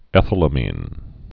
(ĕthə-lə-mēn, -lămən)